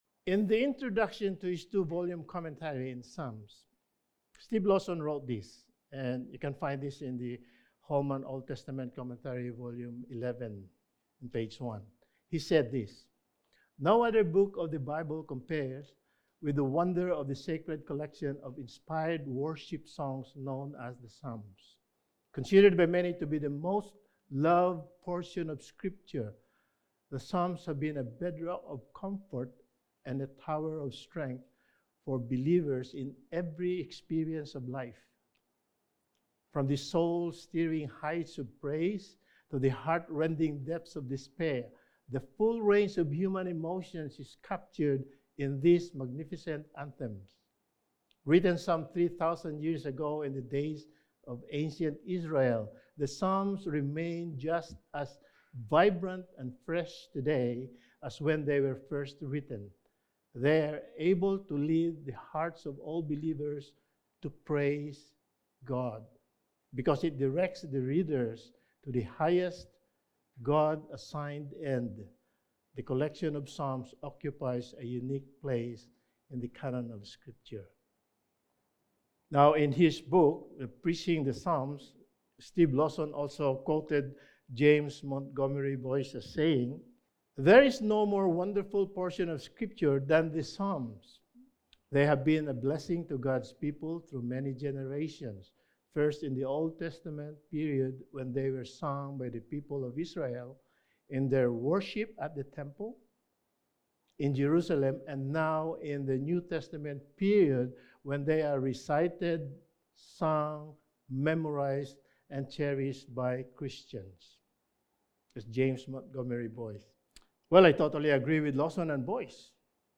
Sermon
Service Type: Sunday Morning Sermon